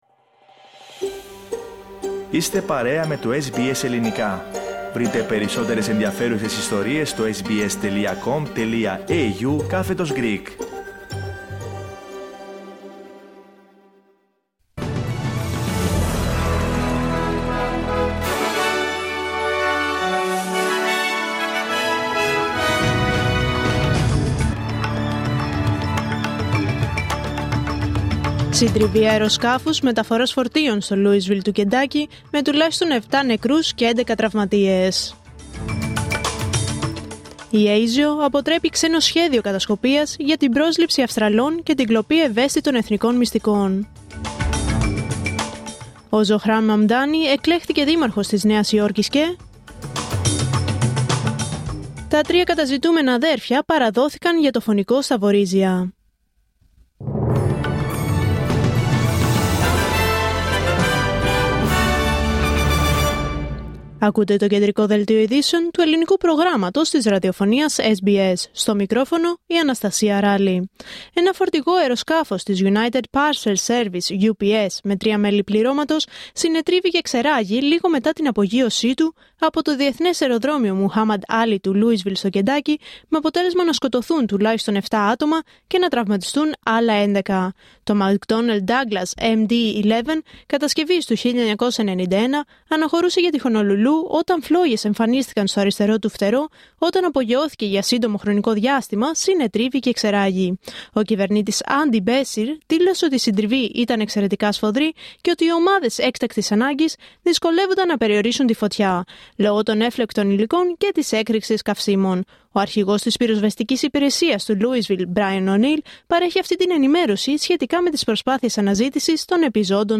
Δελτίο Ειδήσεων Τετάρτη 5 Νοεμβρίου 2025